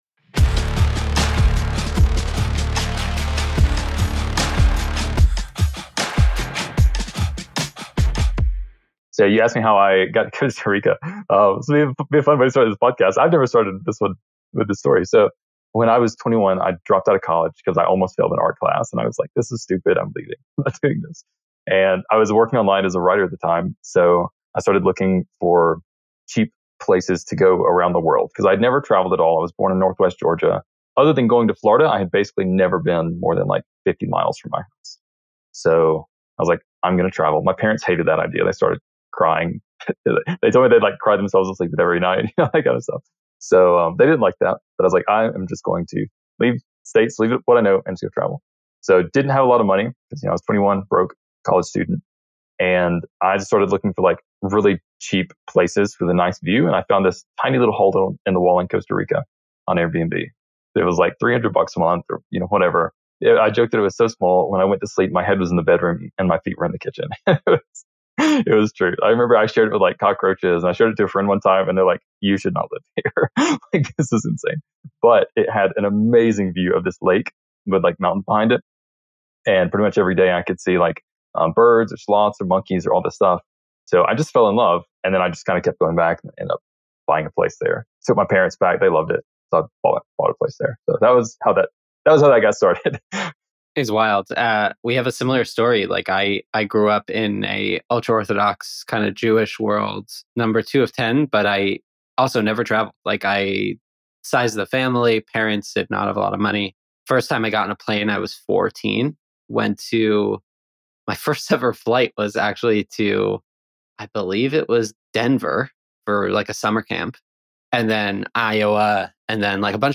The conversation blends life philosophy with tactical advice on marketing, career development, and the tension between art and science in SaaS go-to-market efforts.